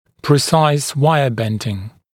[prɪ’saɪs ‘waɪə bendɪŋ][при’сайс ‘уайэ ‘бэндин]формирование точных изгибов на дуге